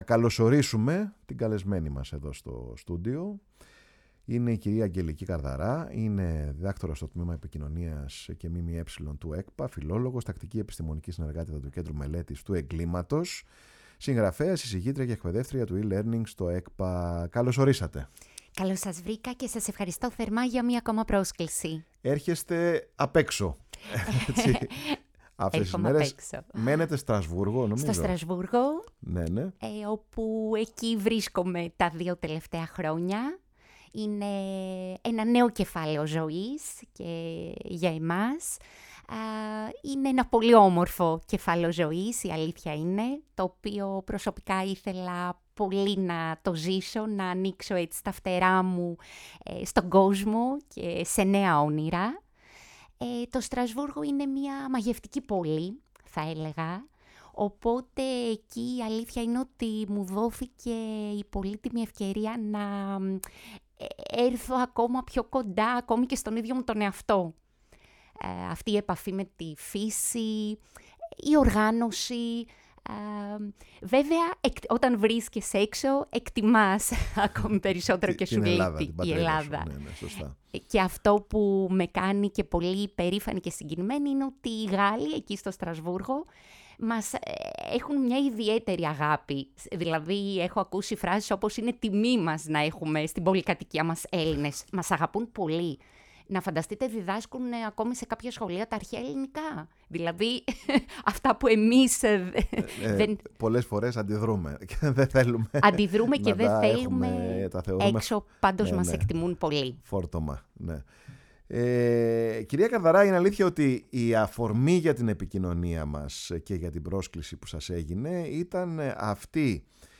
φιλοξένησε στο στούντιο η εκπομπή ”Πάρε τον Χρόνο σου”